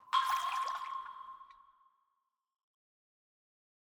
UI_SC03_Water_04.ogg